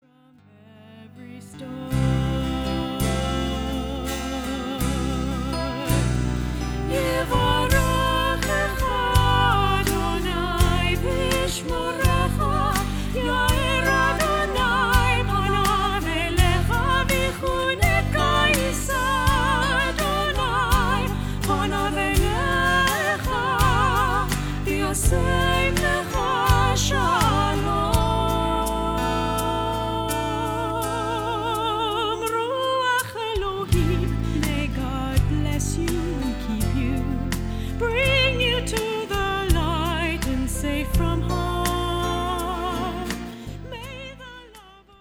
Inspirational music